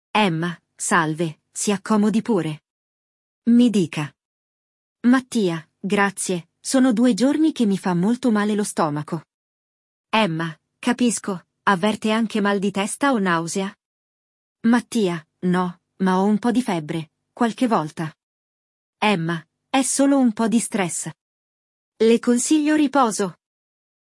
Il dialogo